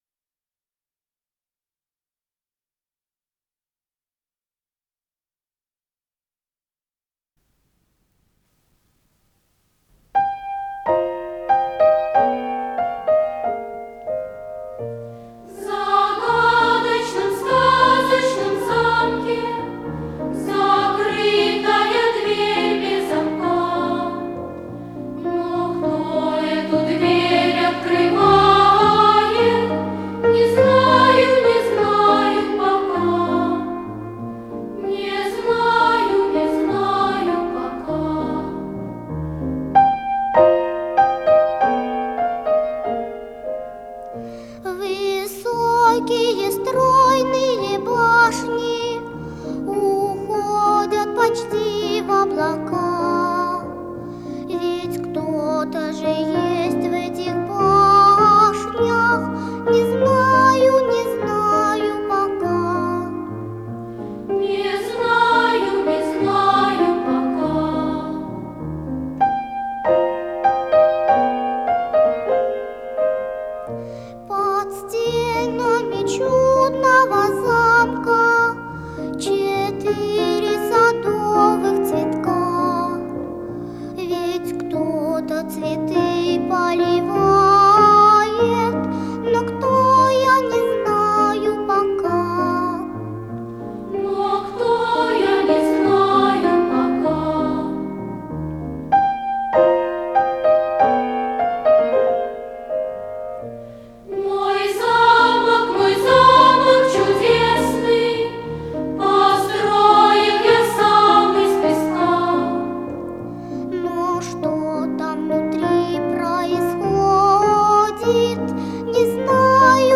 с профессиональной магнитной ленты
фортепиано
ВариантДубль моно